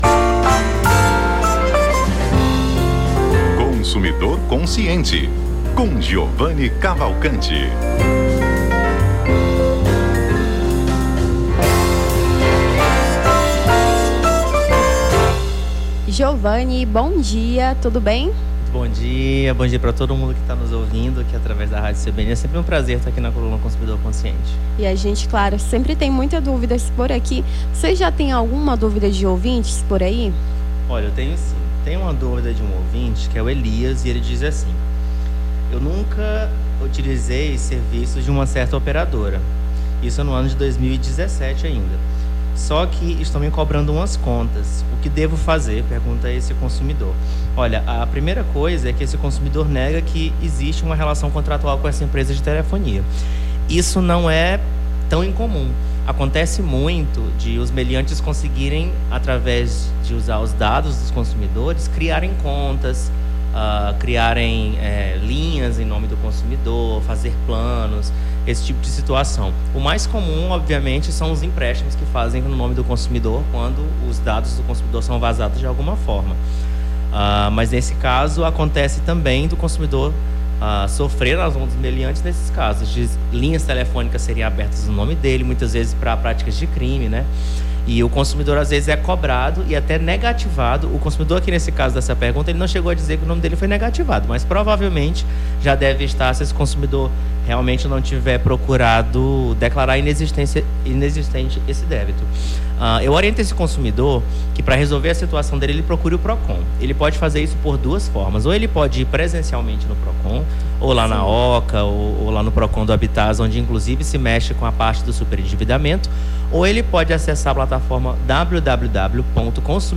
Consumidor Consciente: advogado tira dúvidas sobre direito do consumidor